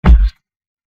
DHL KICK 2.mp3